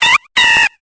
Cri de Pandespiègle dans Pokémon Épée et Bouclier.